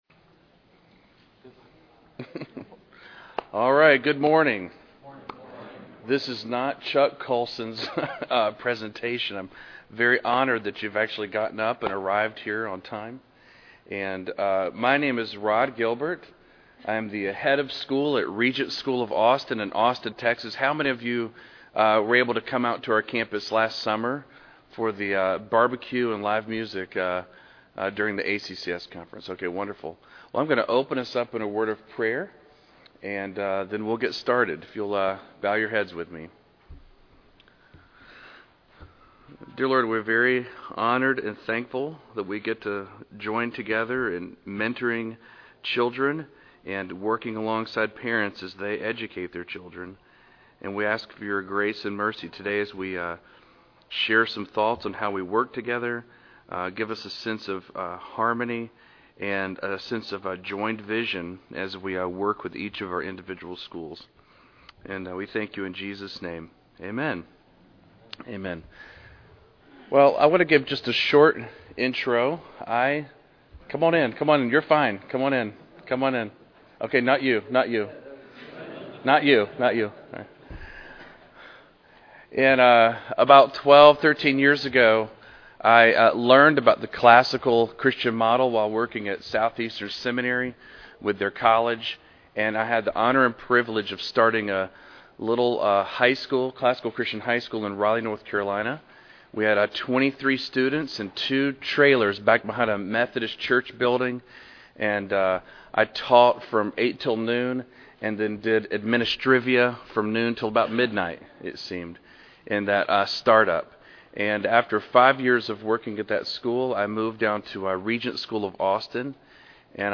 2009 Workshop Talk | 0:52:58 | Leadership & Strategic, Training & Certification
The Association of Classical & Christian Schools presents Repairing the Ruins, the ACCS annual conference, copyright ACCS.